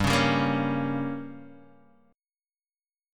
Gdim chord